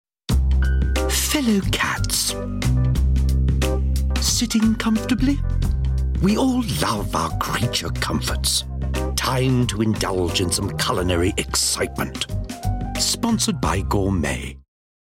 Voice Reel
James Dreyfus - Gourmet Purina Promo.mp3